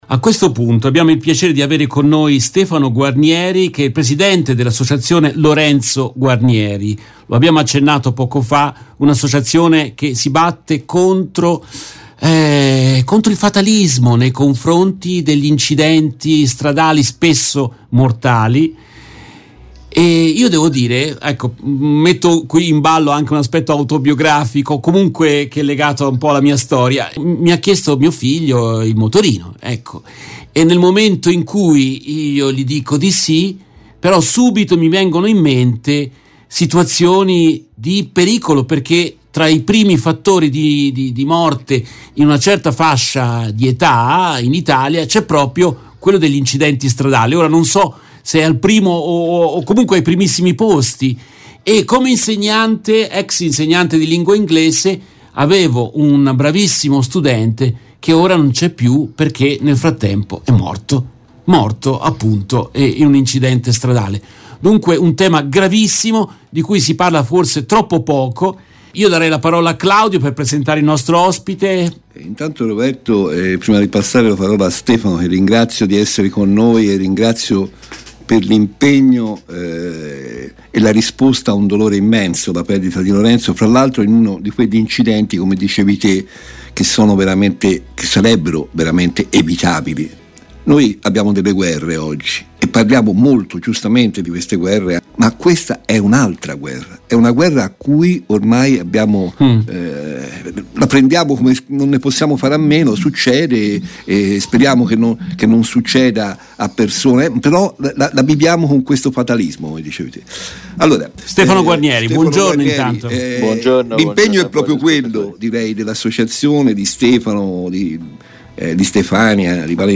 Nel corso della Diretta del Mattino